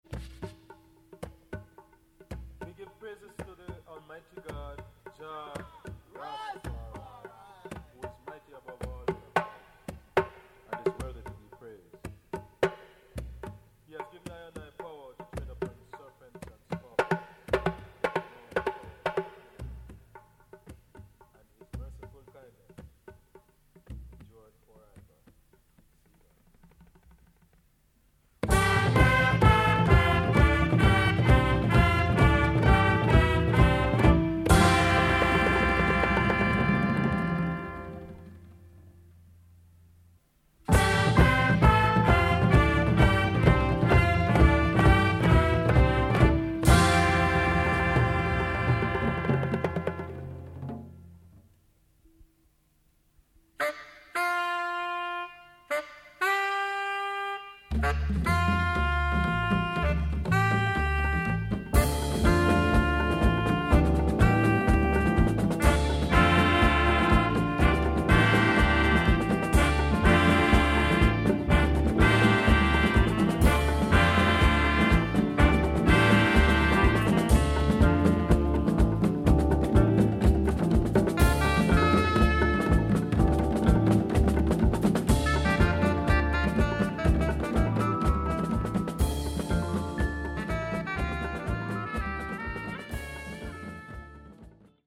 Afro、Funk、Jazz、Calypso、Mentなど様々な音楽を消化したオリジナリティ溢れる傑作。